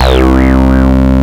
WET FUZZ.wav